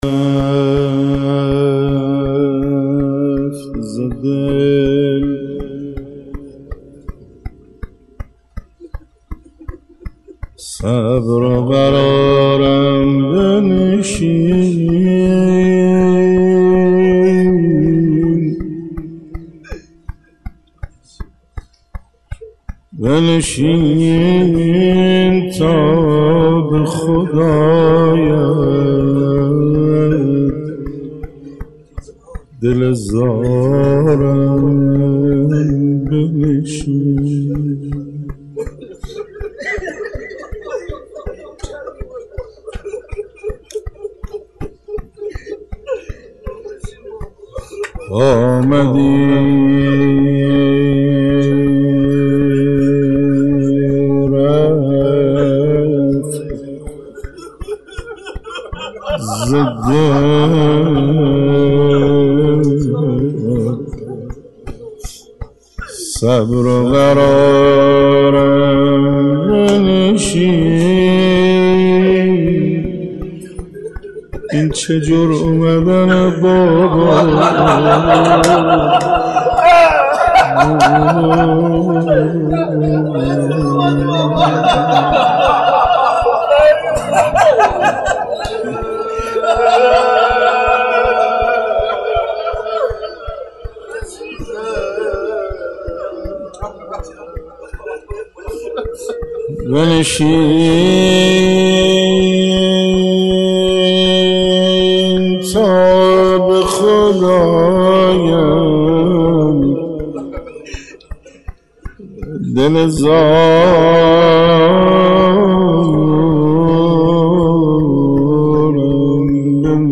شب سوم فاطميه 95 - حسینیه بیت الحسین (ع) - روضه حضرت رقیه (س)